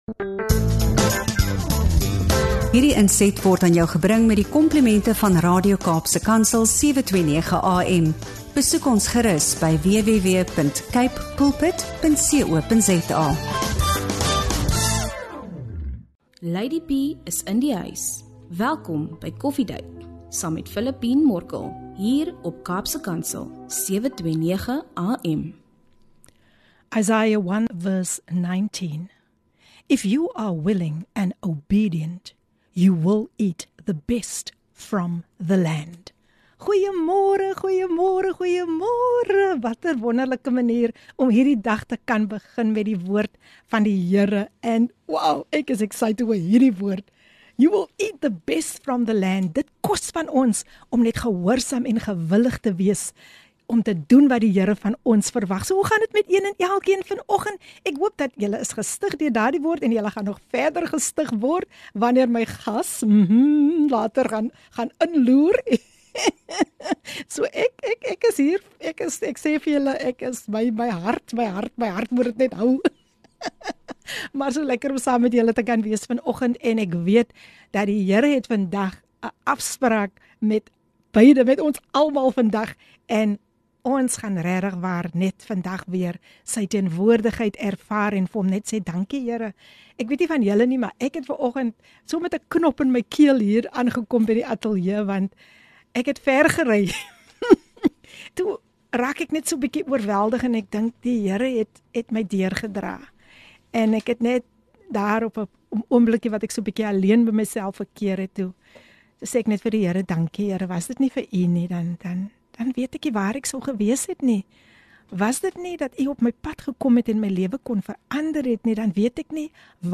'n Hartverwarmende gesprek oor hoop, genesing en geestelike groei.